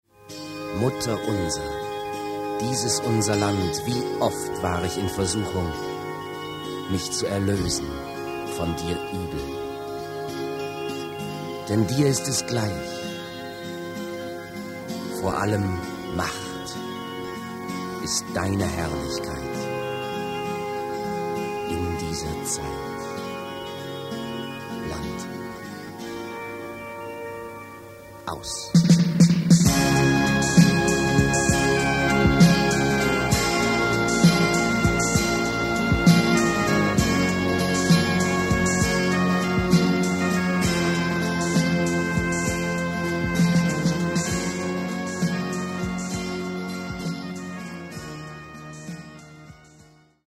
Alle Instrumente und Stimmen: